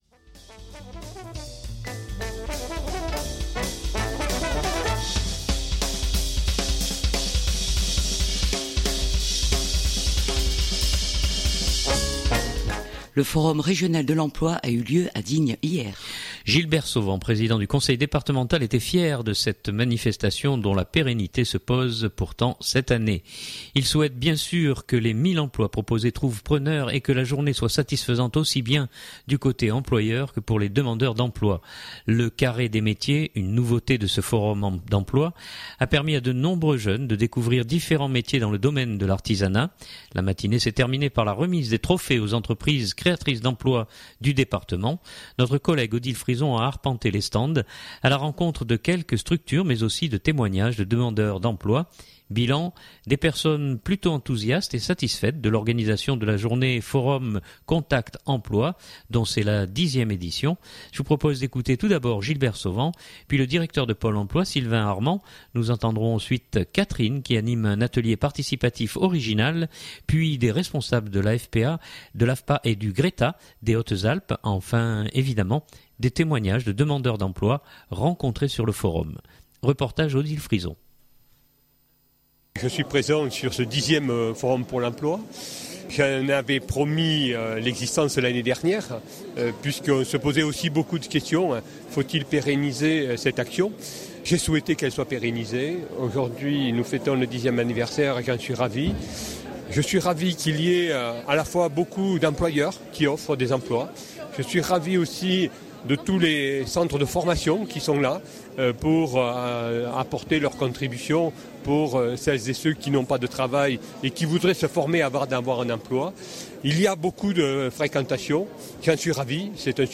Enfin, évidemment, des témoignages de demandeurs d’emploi rencontrés sur le forum.